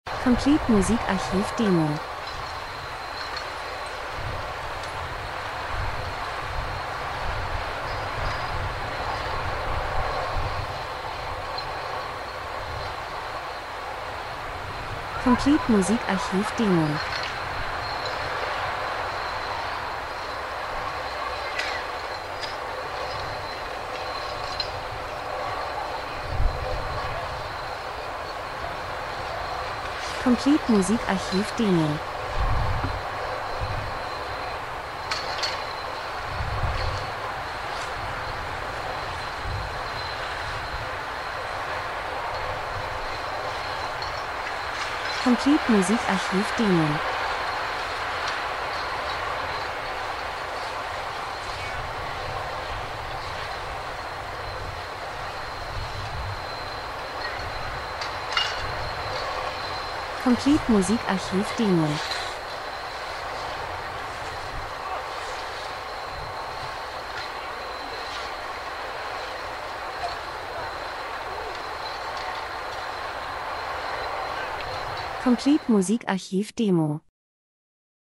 Winter -Sturm Wind Menschen Schnee Piste 01:17